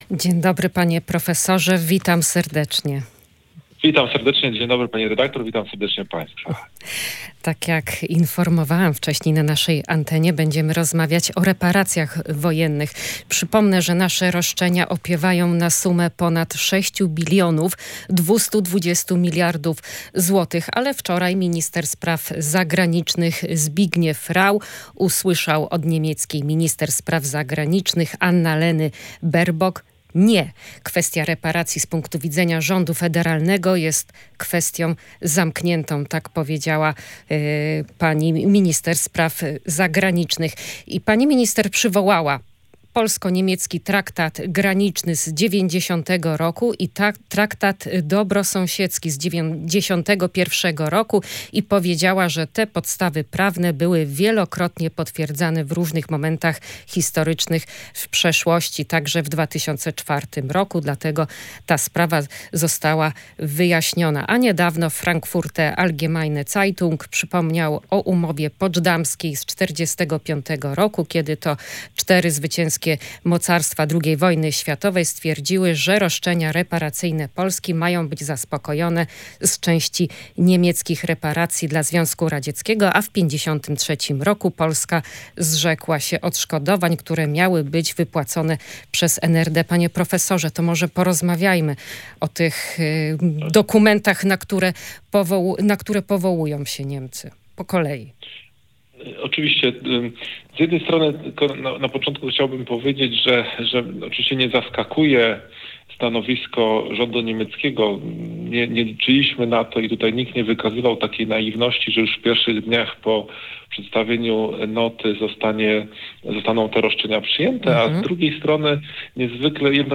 Ta sprawa będzie podnoszona na wszystkich spotkaniach, które będą odbywali przedstawiciele państwa polskiego z rządem niemieckim – mówił w Radiu Gdańsk prof. Krzysztof Szczucki, prezes Rządowego Centrum Legislacji.